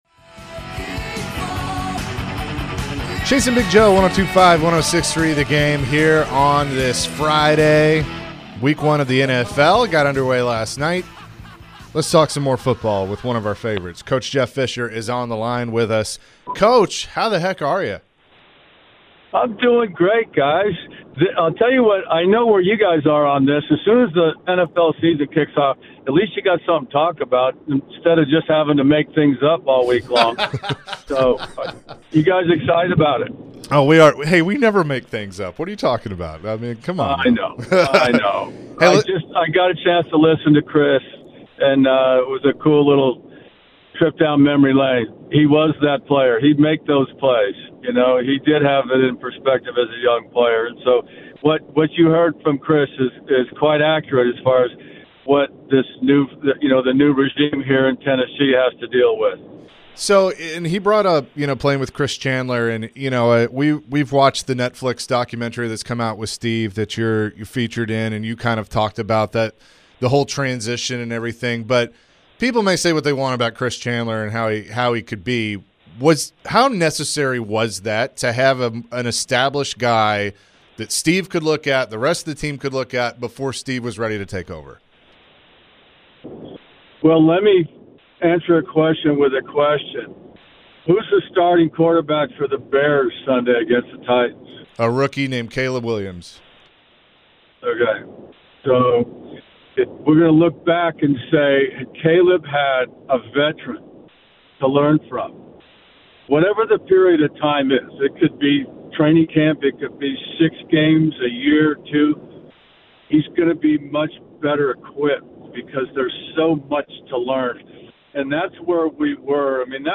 Former Titans head coach Jeff Fisher joined the show and shared his thoughts about the Titans and Bears game coming up this weekend.